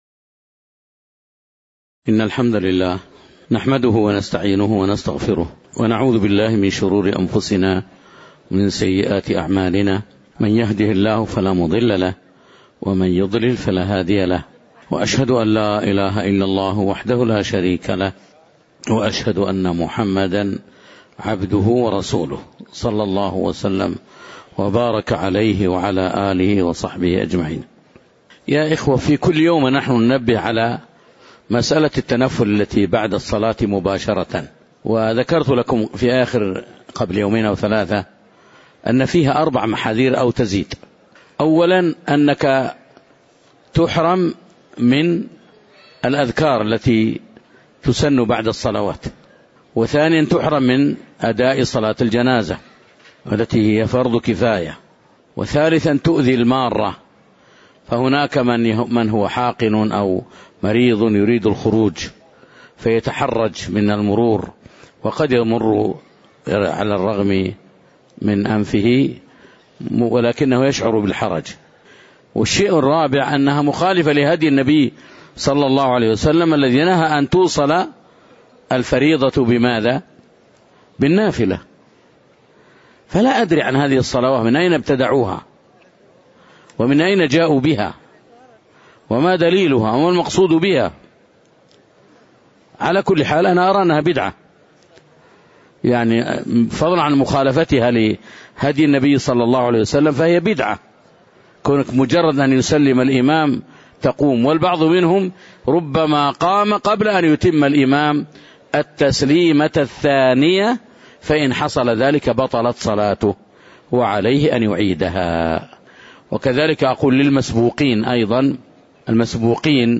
تاريخ النشر ٢١ ربيع الأول ١٤٣٩ هـ المكان: المسجد النبوي الشيخ